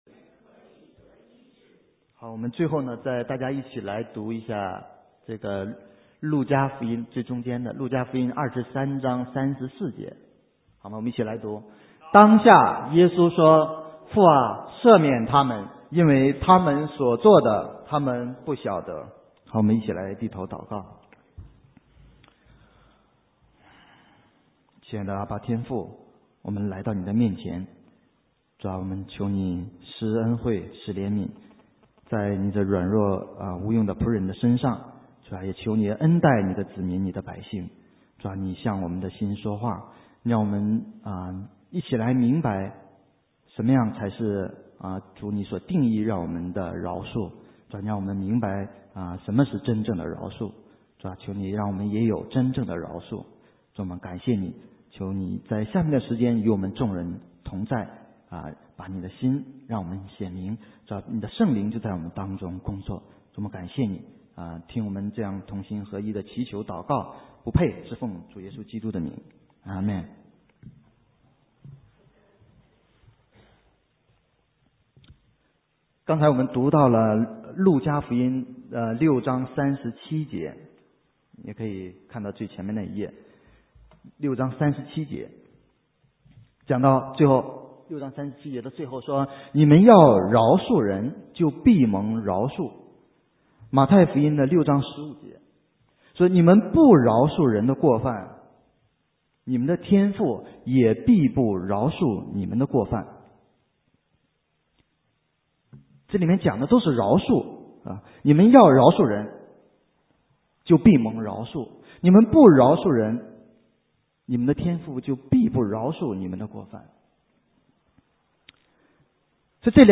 神州宣教--讲道录音 浏览：你们要饶恕人 (2010-02-28)